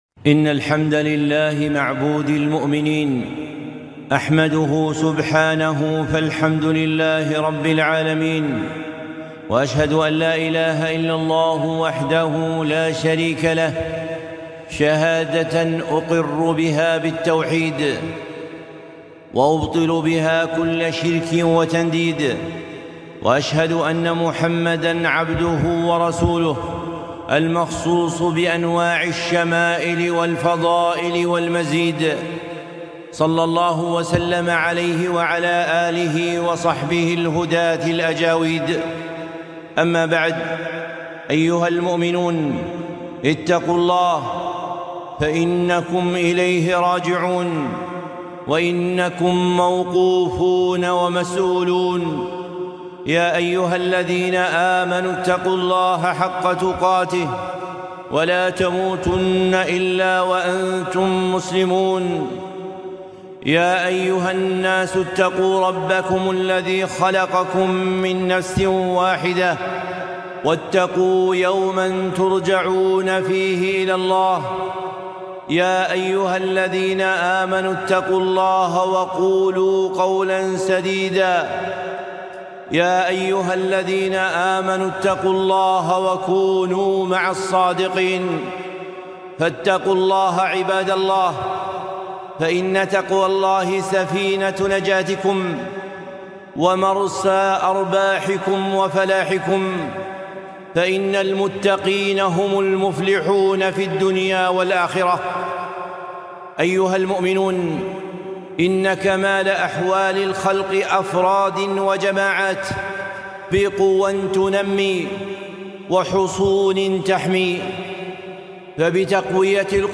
خطبة - تحصنوا أيها المؤمنون